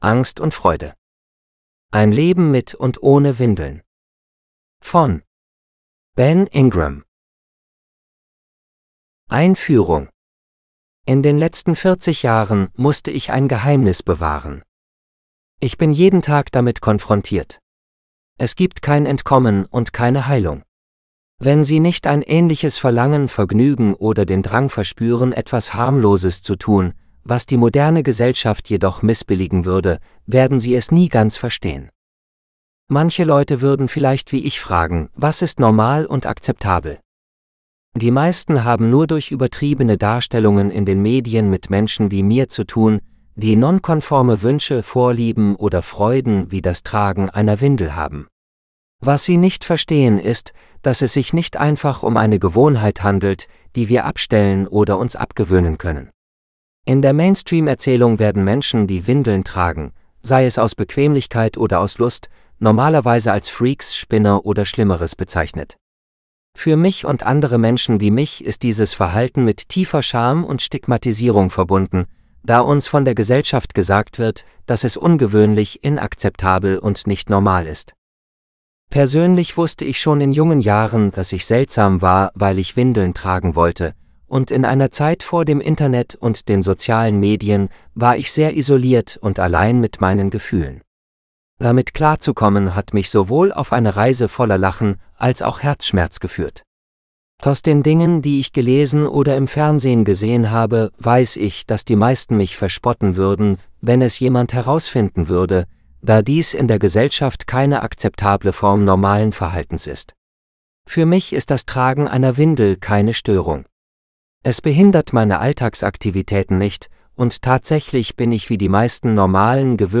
Fear and Joy GERMAN – (AUDIOBOOK – male): $US5.75